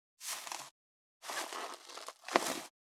646コンビニ袋,ゴミ袋,スーパーの袋,袋,買い出しの音,ゴミ出しの音,袋を運ぶ音,
効果音